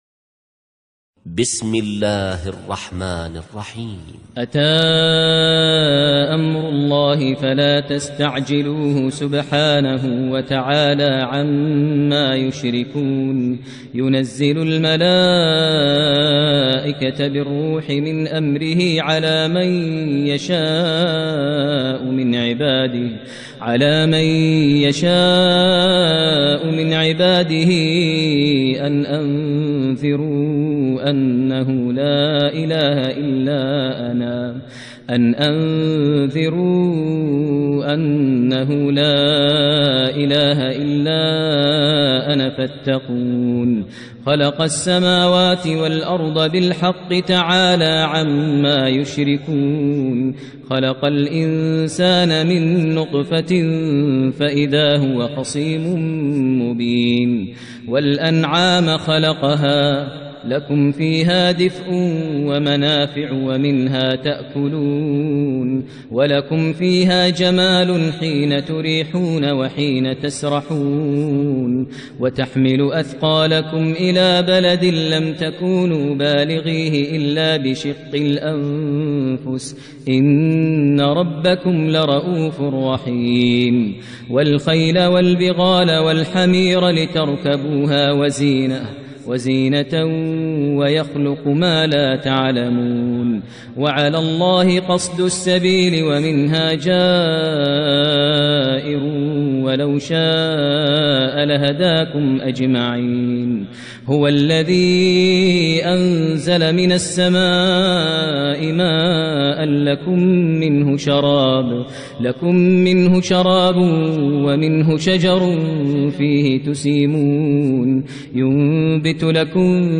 ترتیل سوره نحل با صدای ماهر المعیقلی
016-Maher-Al-Muaiqly-Surah-An-Nahl.mp3